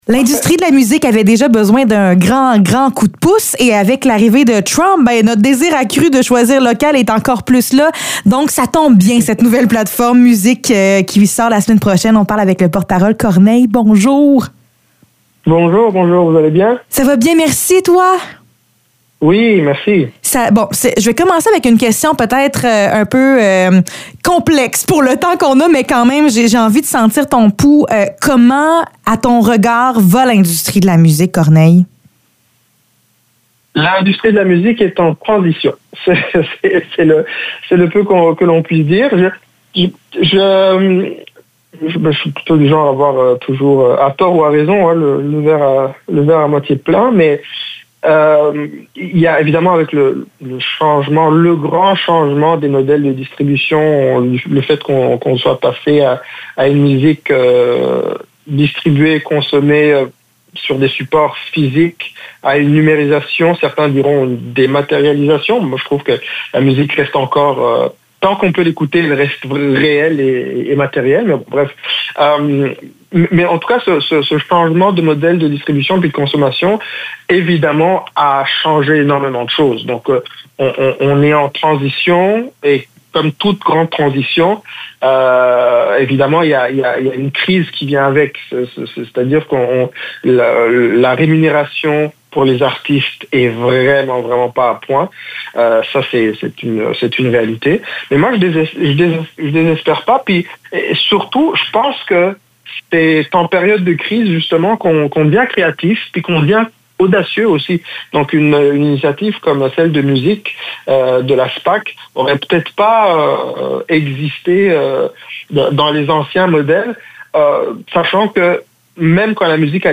Entrevue avec Corneille pour l’application MUSIQC